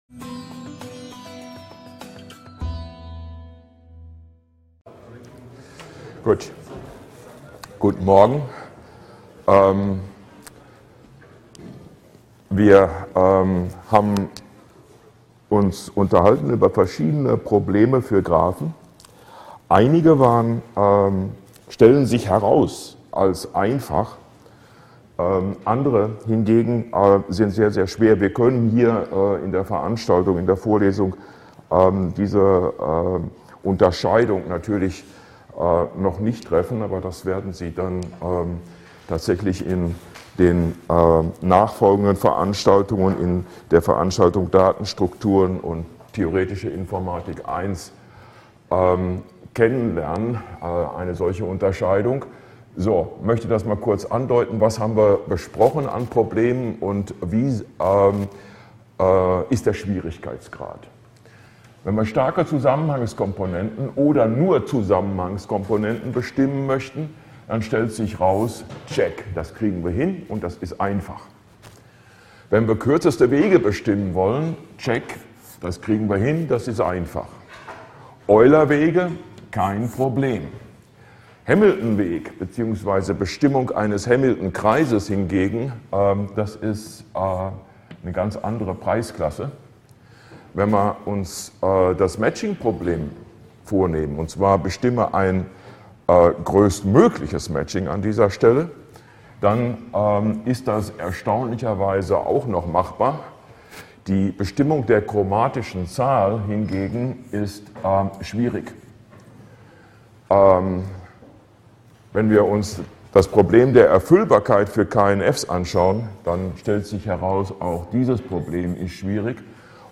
Campus Bockenheim, Jügelhaus, HVI
Es fehlen zudem leider die ersten fünf Minuten.